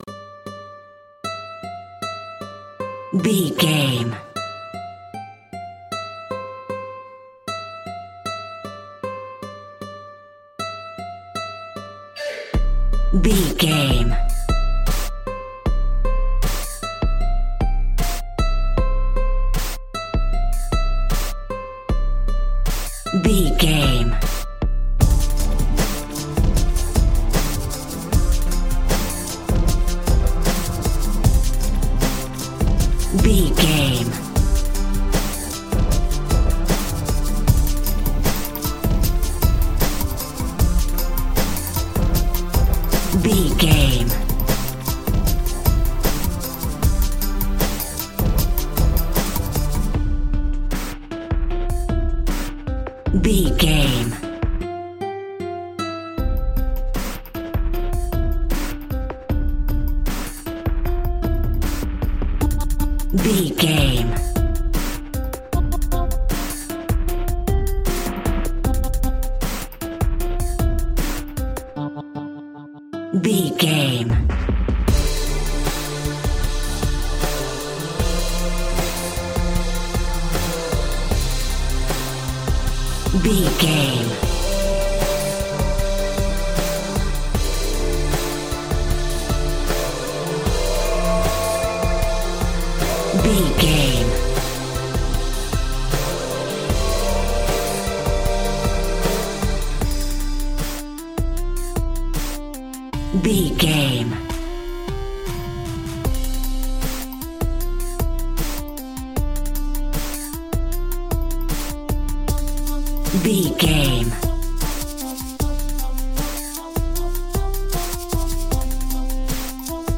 Epic / Action
Fast paced
Aeolian/Minor
dark
futuristic
groovy
harp
synthesiser
drum machine
vocals
Drum and bass
break beat
electronic
sub bass
synth leads
synth bass